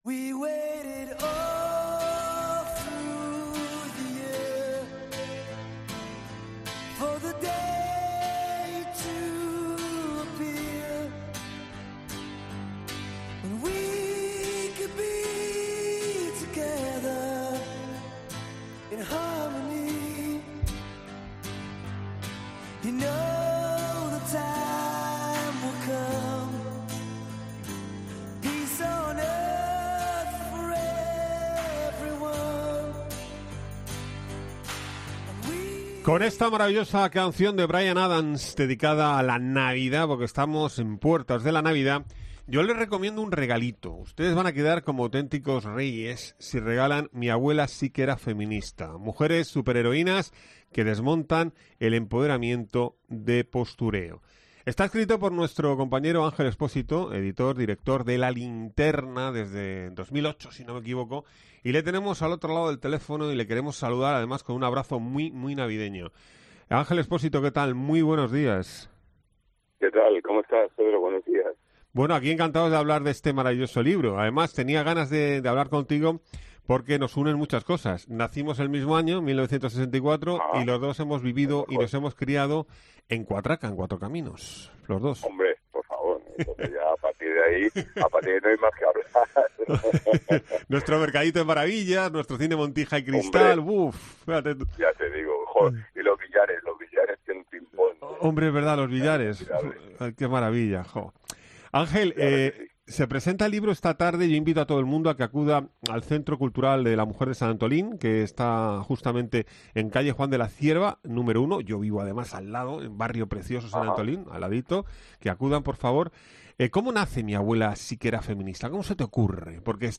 Expósito también ha resaltado en la entrevista que pueden escuchar en COPE Murcia que cuando oye hablar a algunas políticas de feminismo siente emociones encontradas, sobre todo cuando se viven experiencias como la de otras mujeres en Senegal, que ven como sus hijos se embarcan en cayucos, rumbo a Canarias y no vuelven a verlos nunca.